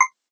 Index of /97/menu/sfx/
ping_5.ogg